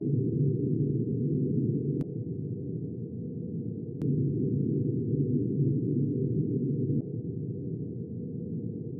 Active noise cancellation
in a room